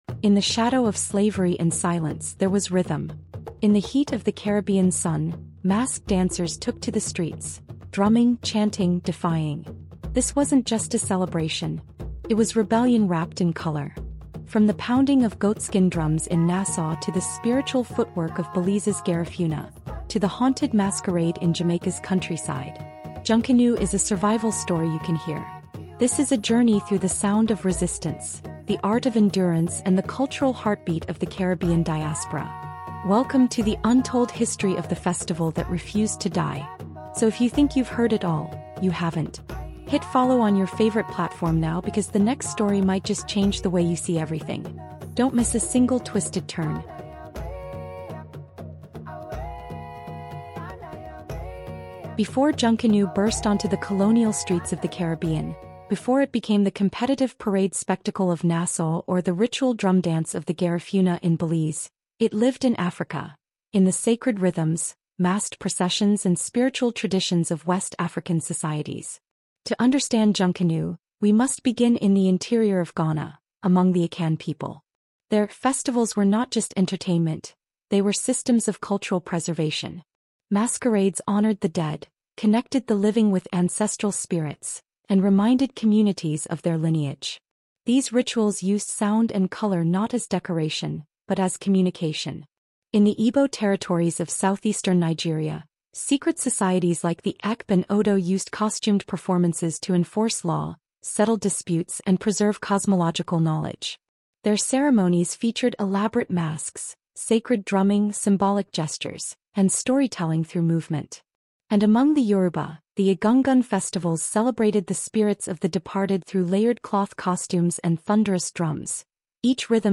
CARIBBEAN HISTORY: The Story of Junkanoo — Resistance in Rhythm is a cinematic, longform Caribbean audiobook documentary blending Caribbean history, music, and heritage into an emotionally immersive journey. This episode traces the African roots and rebellious origins of Junkanoo, revealing how enslaved Africans in colonial Nassau, Jamaica, and Belize turned hidden revolts into bold expressions of cultural resilience.